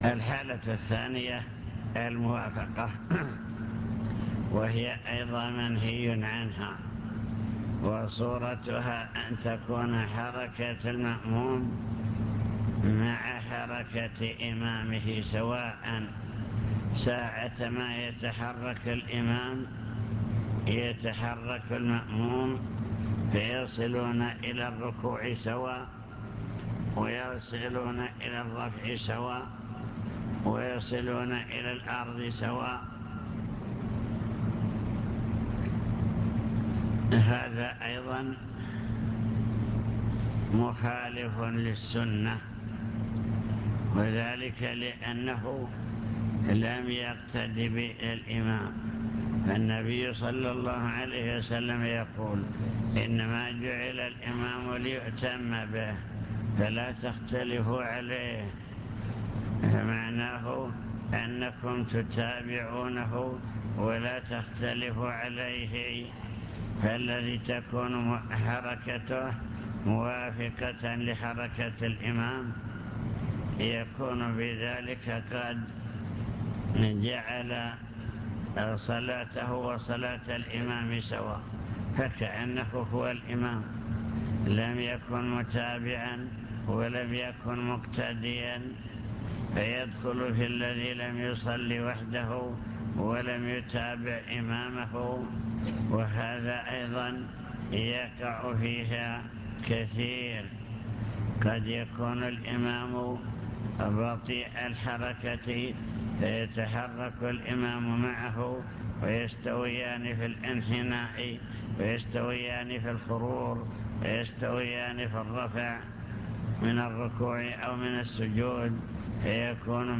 المكتبة الصوتية  تسجيلات - محاضرات ودروس  محاضرة في بدر بعنوان: وصايا عامة حالات المصلين خلف الإمام